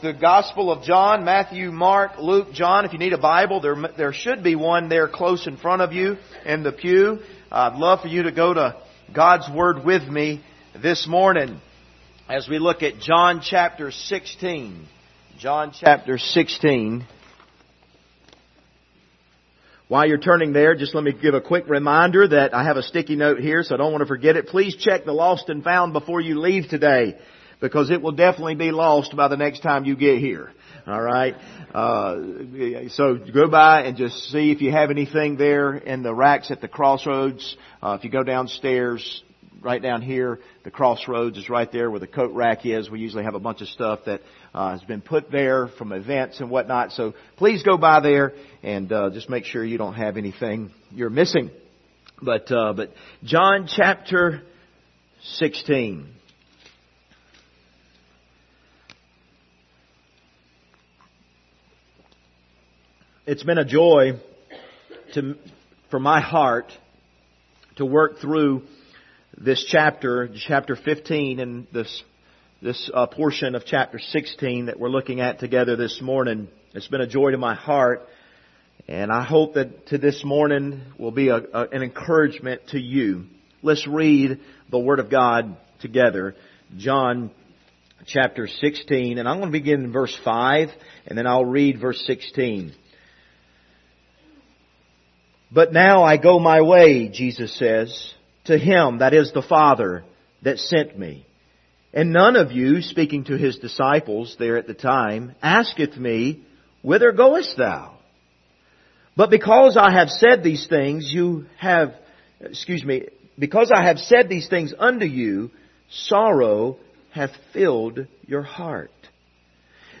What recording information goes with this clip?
Passage: John 16:5-16 Service Type: Sunday Morning